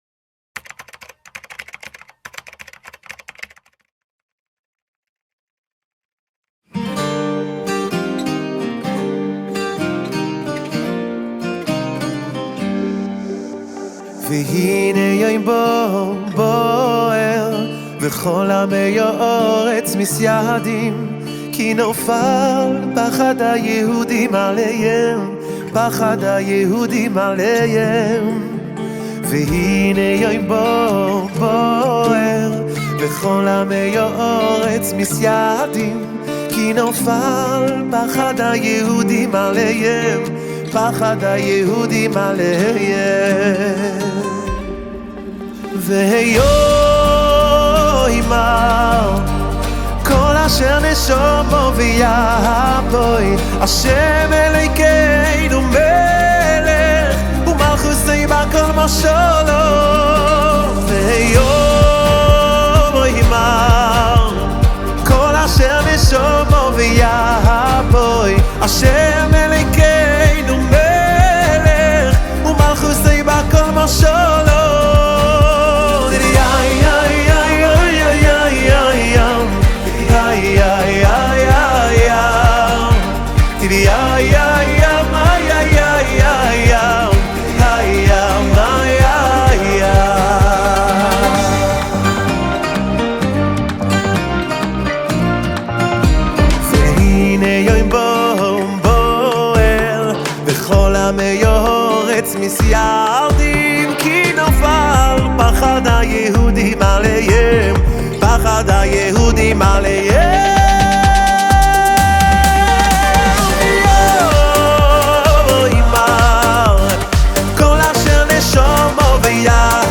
שיר שמשלב מסורת חסידית עם נגיעות מודרניות וחדשניות.